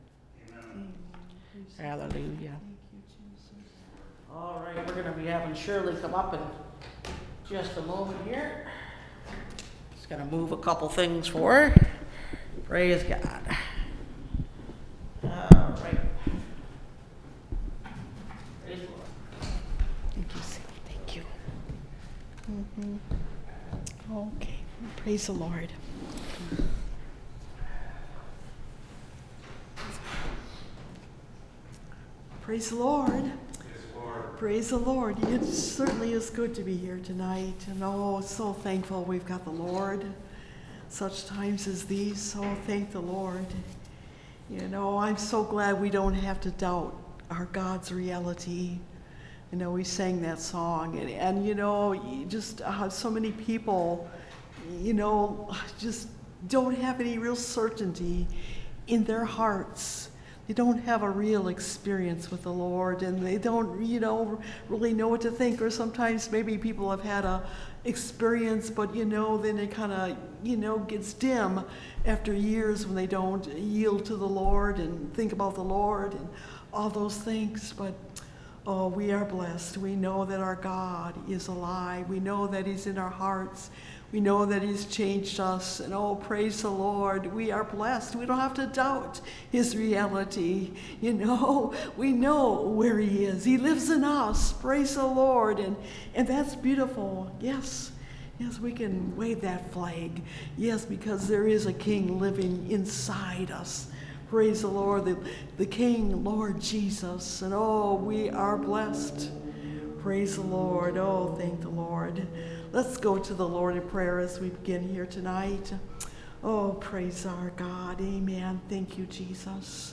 The Cup In The Lord’s Hand (Message Audio) – Last Trumpet Ministries – Truth Tabernacle – Sermon Library